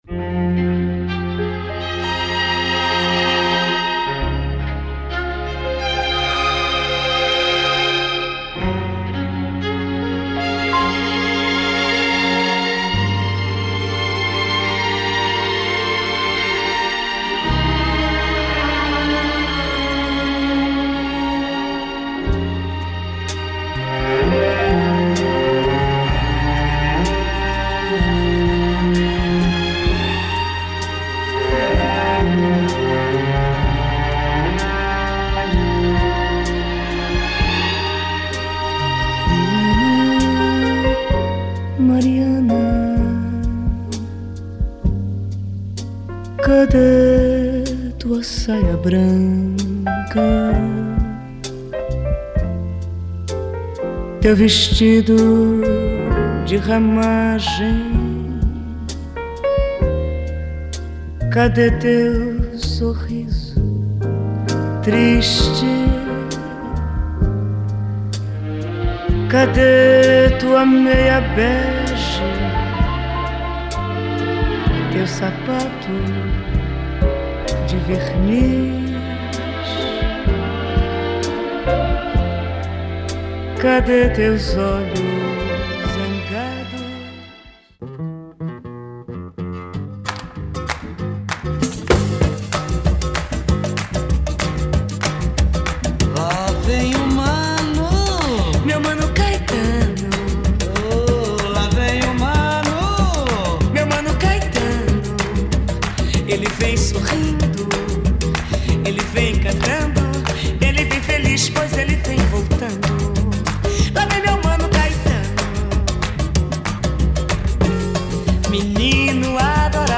Brazilian singer
soft jazz
LP